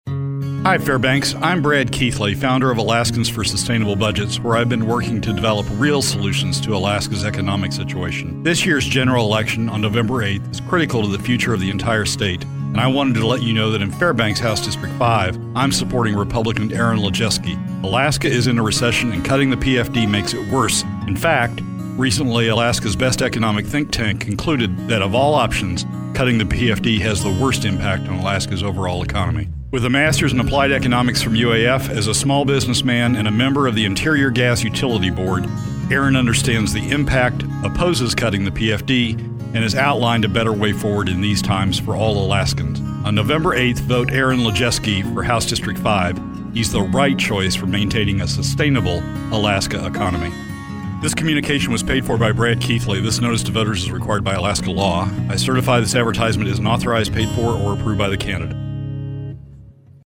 The following radio ad and some related digital is going up today and will run through election day.